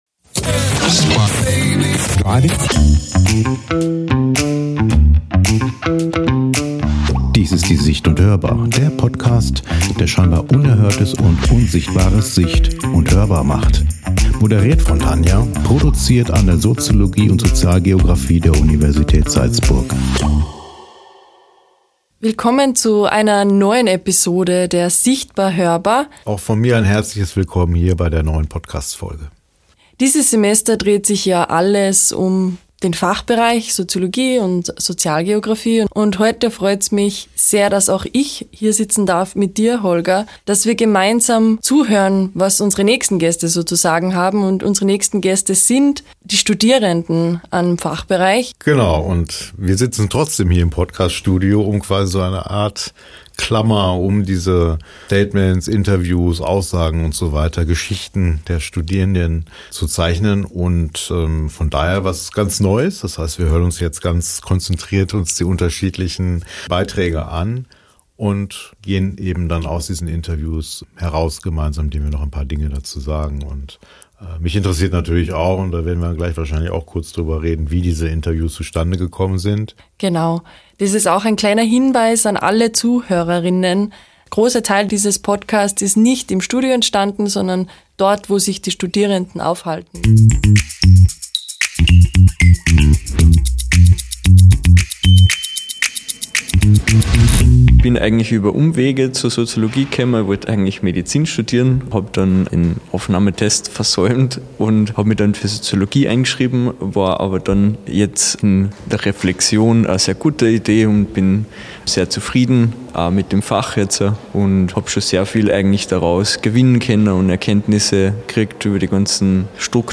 Sie lassen uns an ihrer Entscheidung fürs Studium teilhaben und erzählen über Exkursionen, über Praktika und vieles mehr. Kurzer Hinweis: Es handelt sich um eine etwas andere Folge, denn die Aufnahmen fanden nicht im Podcaststudio statt, sondern in den Räumen der NAWI und der GESWI.